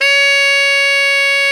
SAX TENORF15.wav